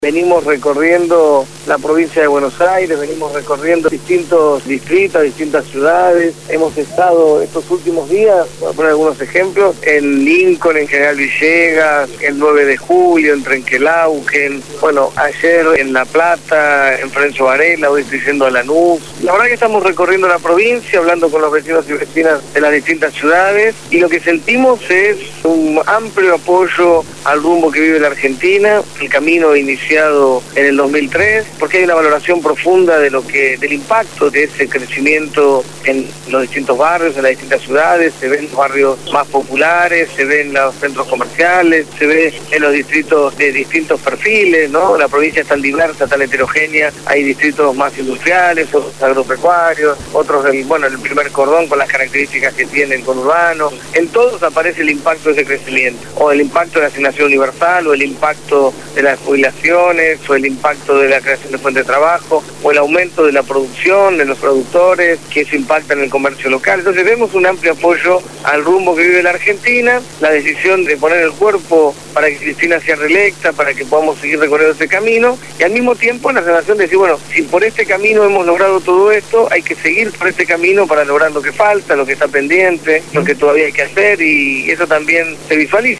Lo dijo Martín Sabbatella, candidato a gobernador bonaerense por Nuevo Encuentro (cuya boleta llevará la fórmula presidencial Cristina Fernandez-Amado Boudou) en «Desde el Barrio».